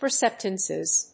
音節per･cep･tive･ness発音記号・読み方pərséptɪvnəs